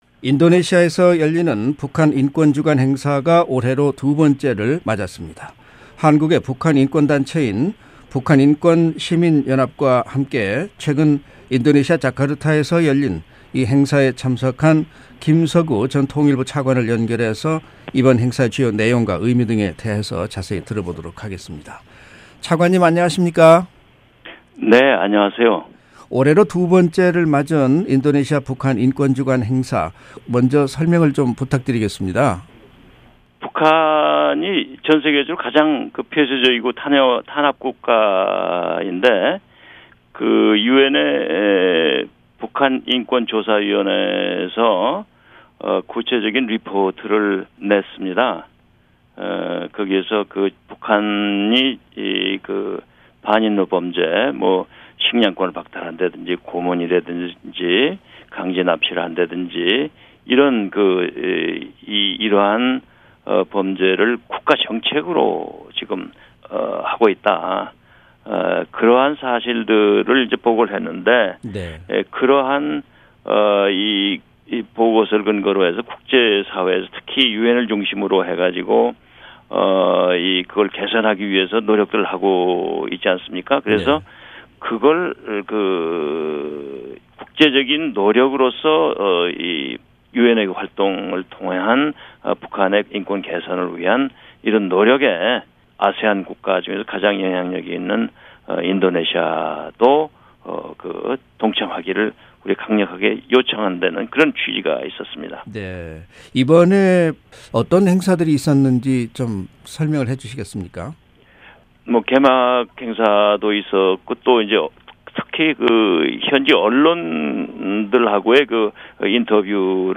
[인터뷰] 김석우 전 통일부 차관